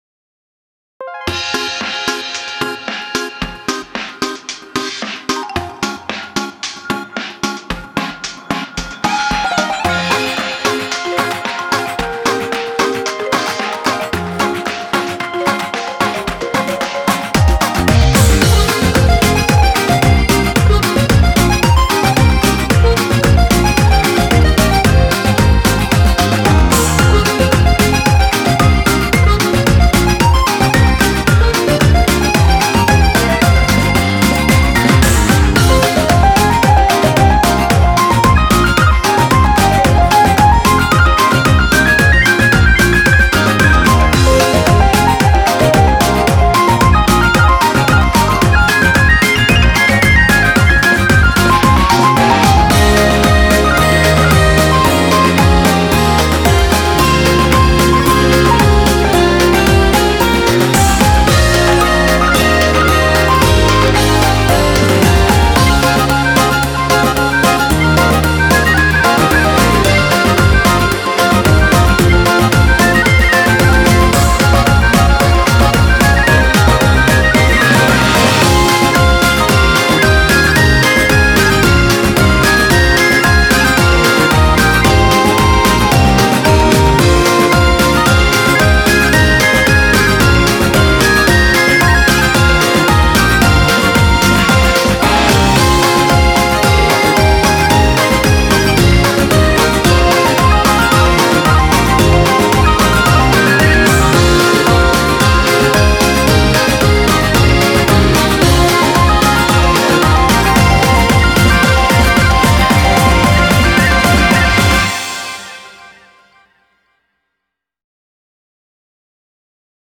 BPM112
Audio QualityPerfect (High Quality)
Genre: WORLD/ELECTRONICA.